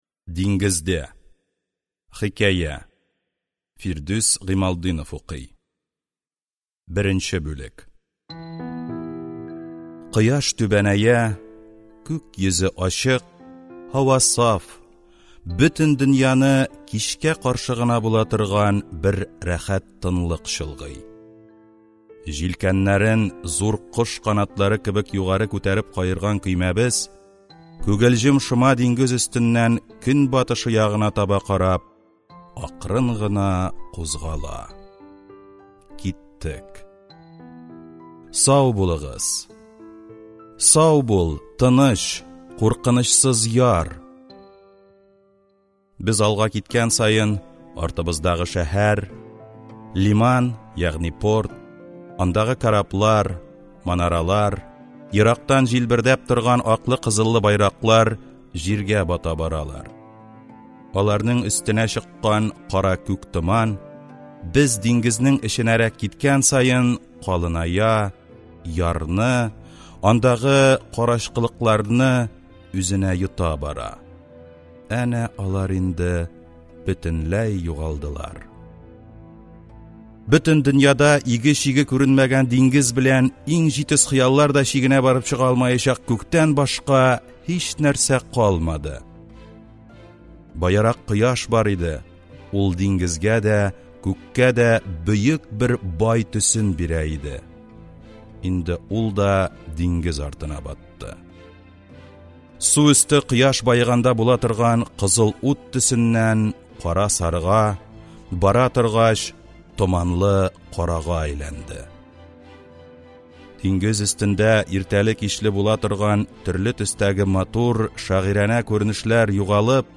Аудиокнига Хикәяләр | Библиотека аудиокниг